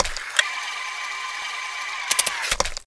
rifle_barrel_spin.wav